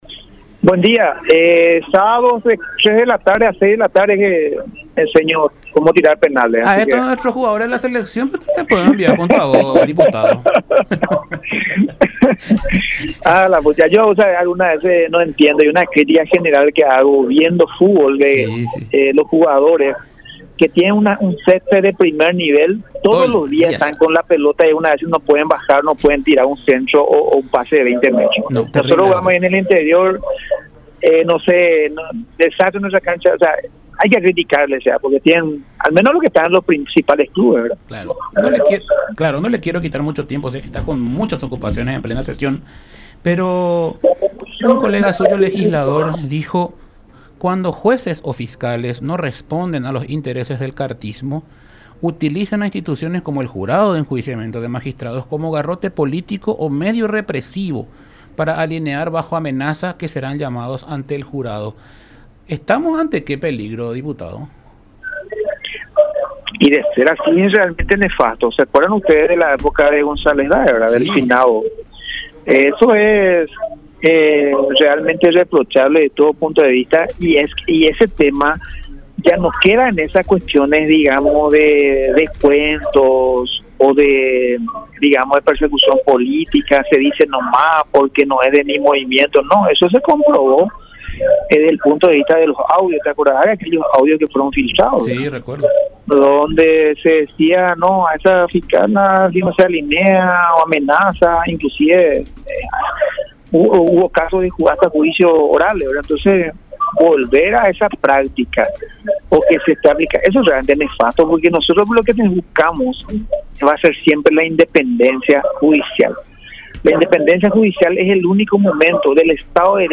Édgar Acosta, diputado liberal.